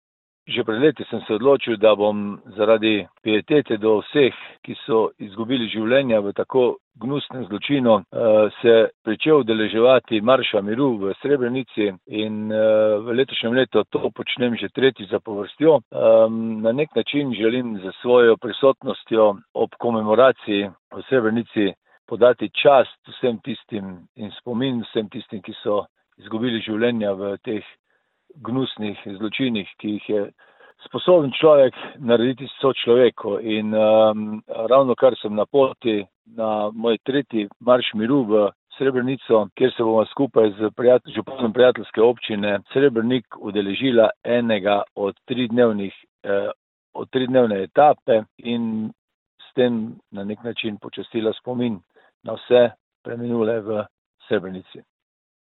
Med pohodniki tudi radeljski župan
izjava Alan Bukovnik Srebrenica  za splet.mp3